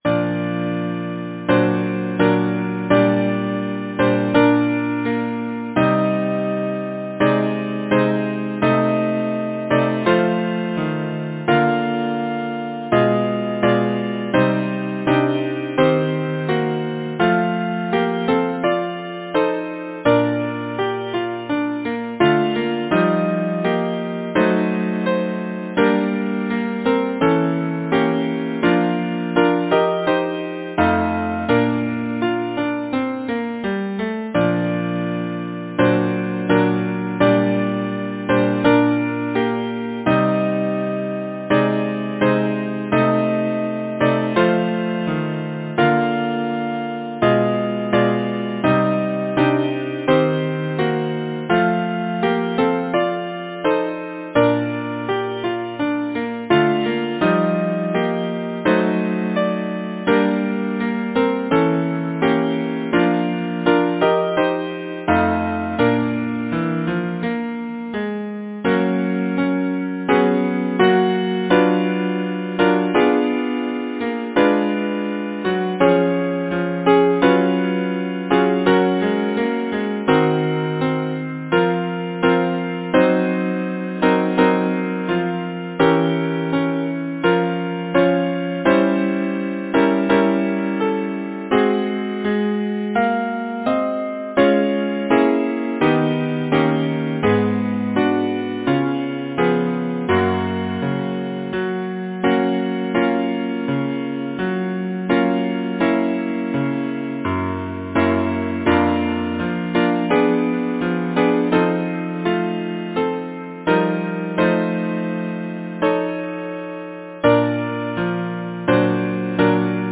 Title: Stars of the summer night Composer: Berthold Tours Lyricist: Henry Wadsworth Longfellow Number of voices: 4vv Voicing: SATB Genre: Secular, Partsong
Language: English Instruments: A cappella
First published: 1885 Novello, Ewer, and Co. Description: This partsong was originally a solo song, arranged for mixed voices by the composer.